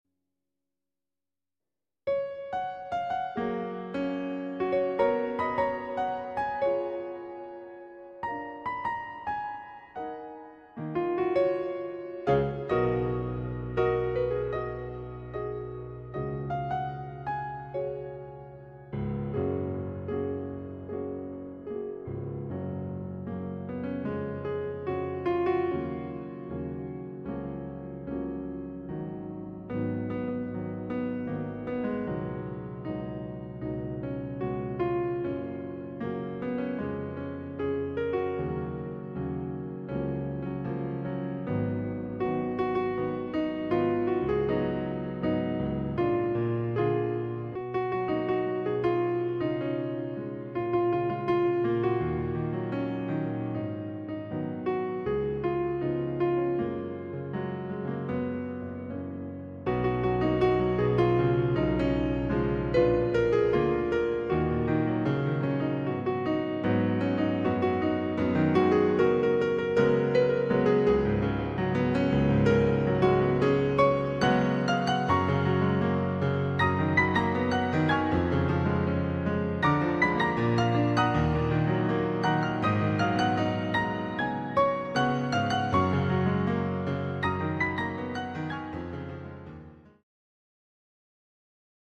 J-pop 弾いてみた編